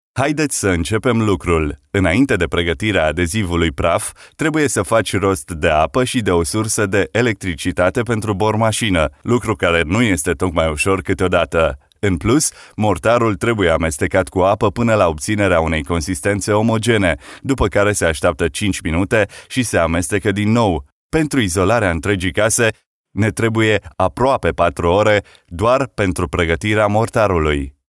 Commercial